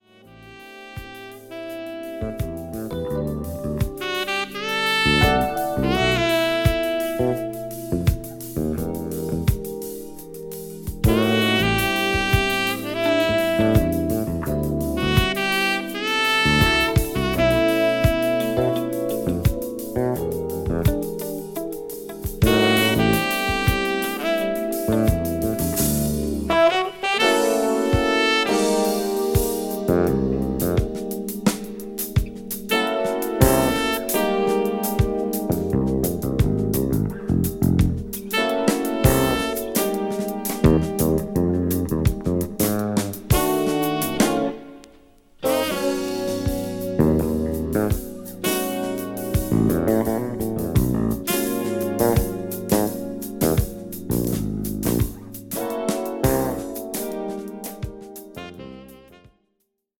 FUSION
リーダー作でありながらデュオ・アルバムのような趣も感じさせる好フュージョン作です。